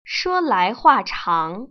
[shuō lái huà cháng] 수어라이후아창